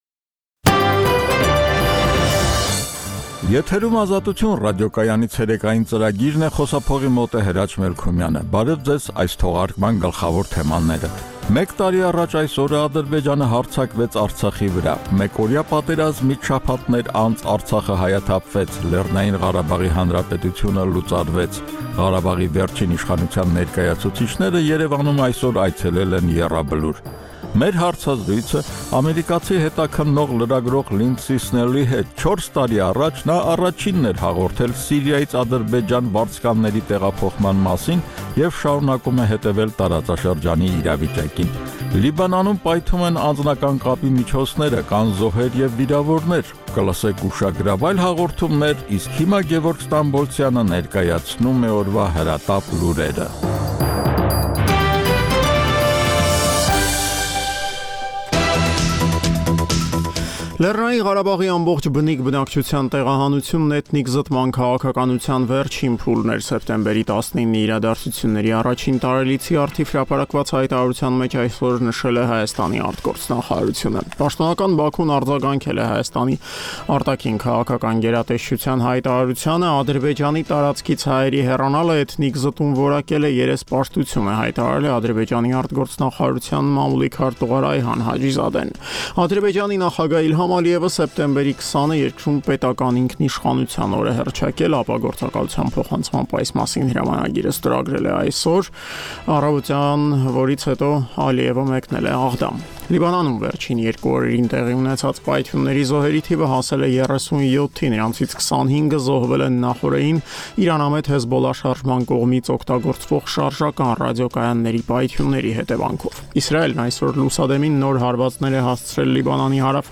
Տեղական եւ միջազգային լուրեր, ռեպորտաժներ, հարցազրույցներ: